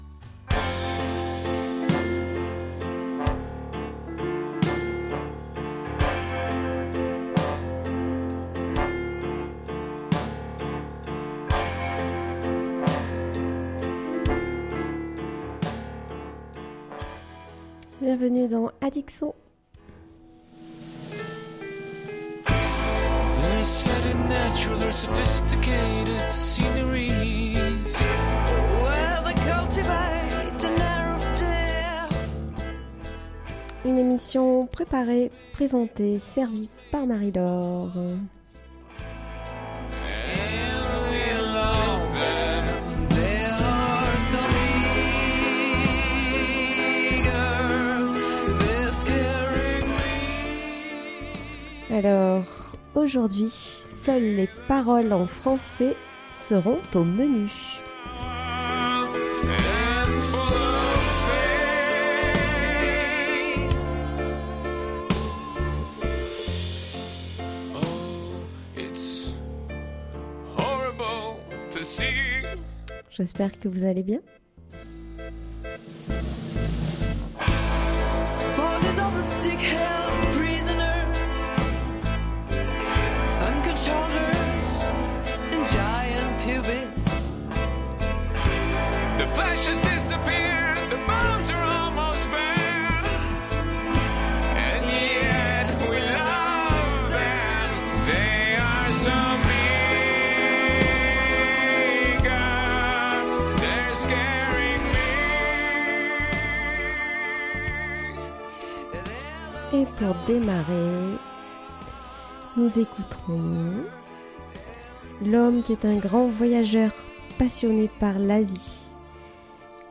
Addic son, une émission musicale proposée